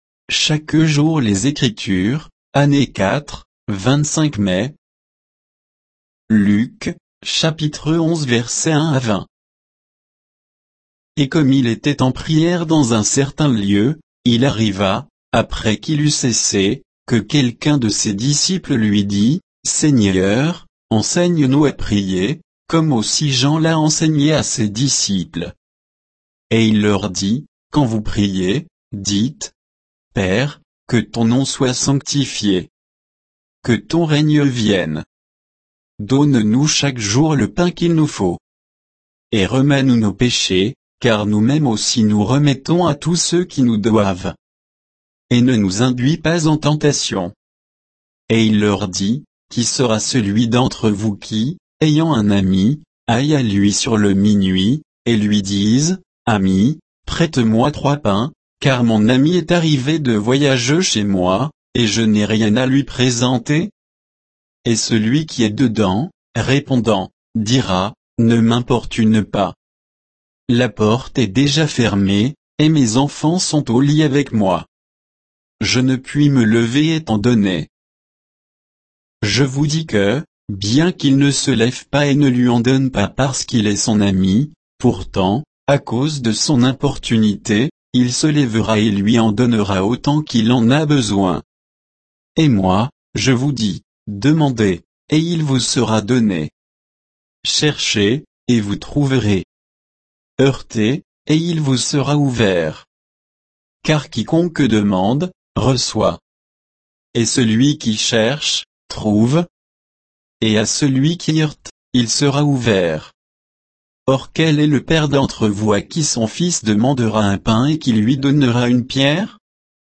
Méditation quoditienne de Chaque jour les Écritures sur Luc 11